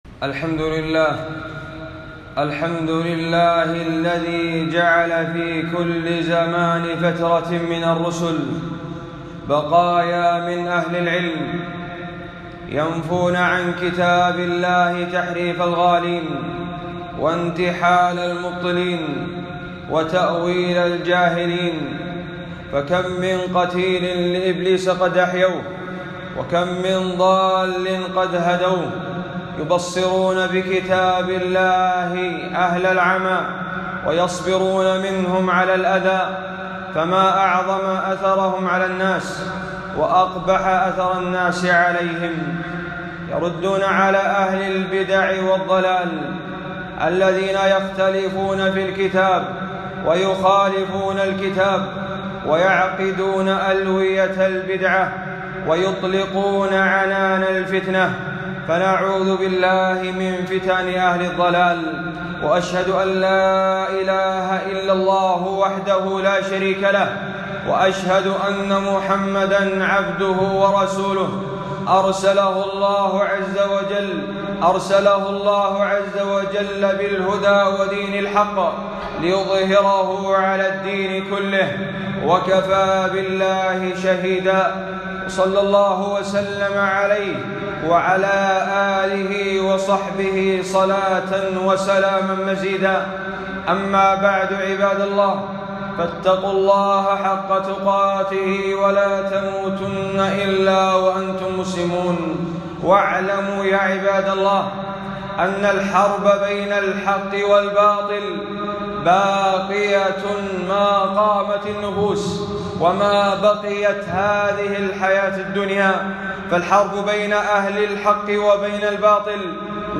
خطبة - الرد على من يتهم الإسلام والسلفية والعلماء بأنهم عائق التطور